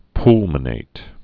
(plmə-nāt, pŭl-)